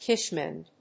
アクセント・音節kítchen・màid